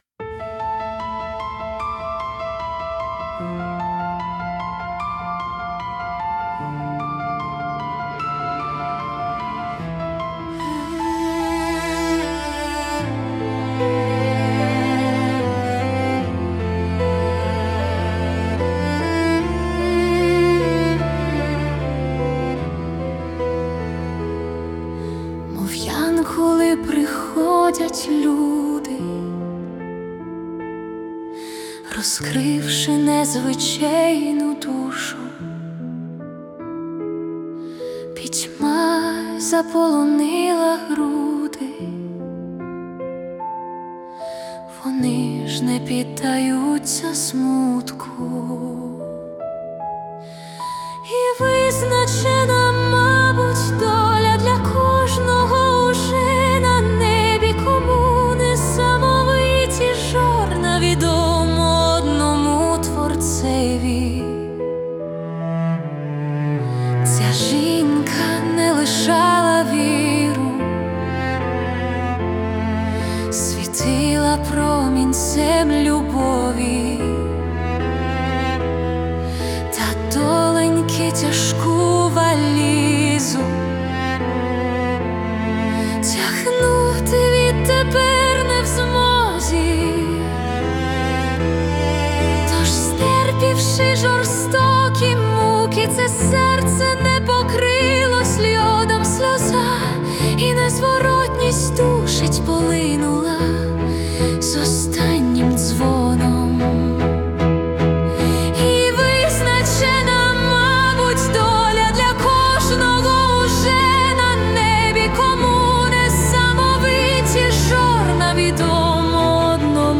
Прекрасна пісня, дякую!